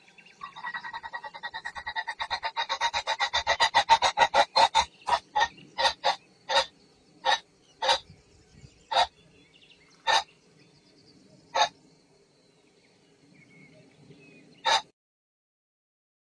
雉鸡叫声 野山鸡“咯咯咯”鸣叫声